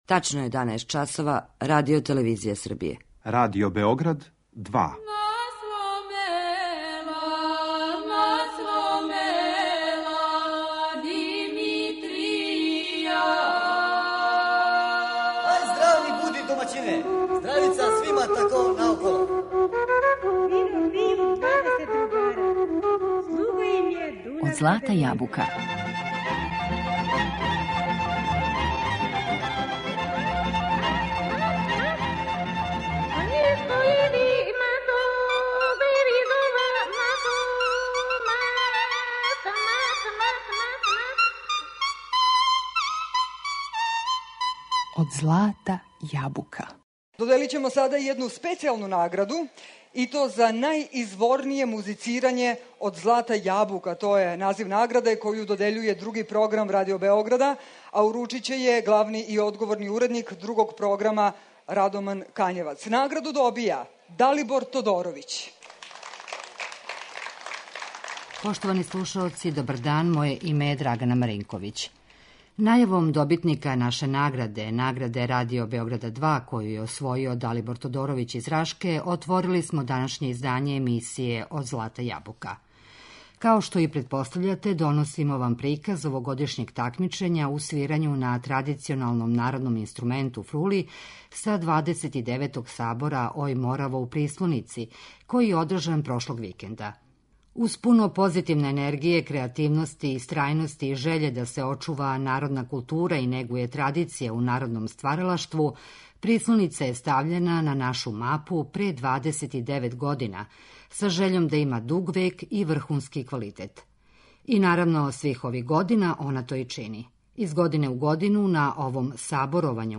Емисија изворне народне музике
Овог петка водимо вас на 29. Сабор фрулаша "Ој Мораво", у Прислоници.
Емитоваћемо снимак прве такмичарске вечери, која је одржана 23. јула.